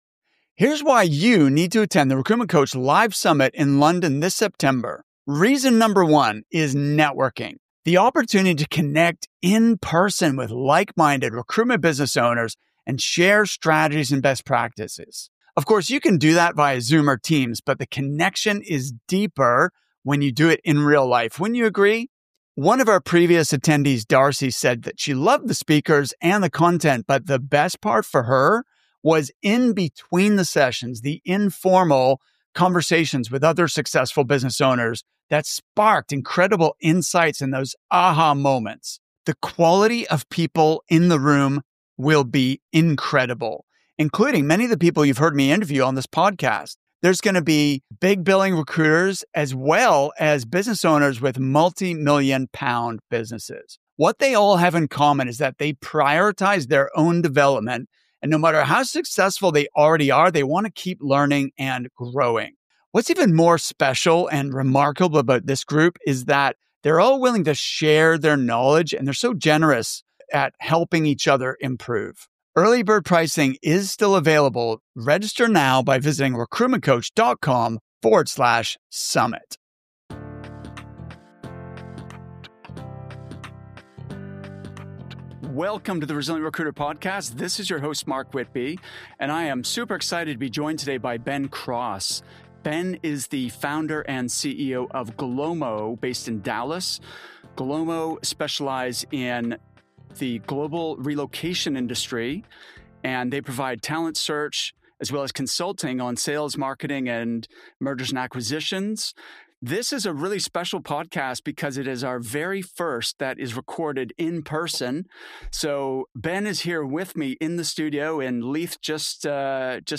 In this interview, we break down exactly how he achieved those remarkable results.